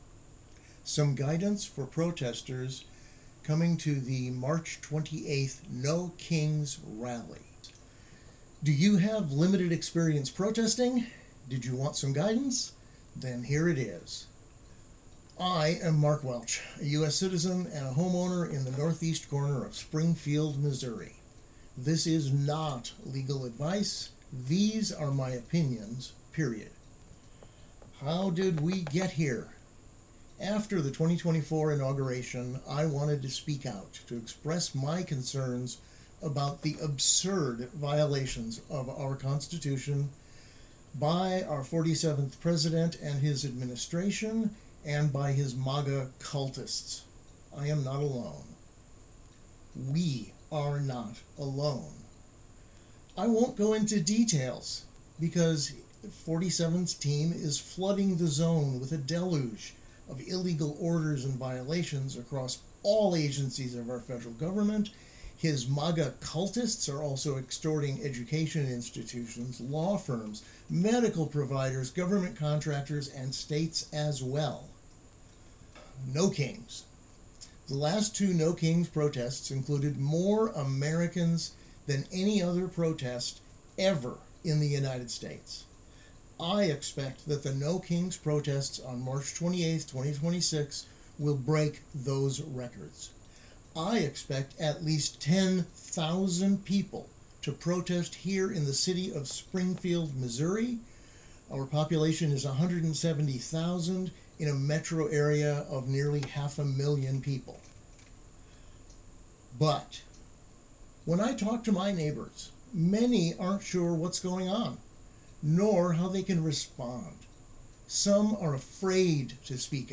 You can also download my full audio narration (.wav file, 48MB, 25 minutes).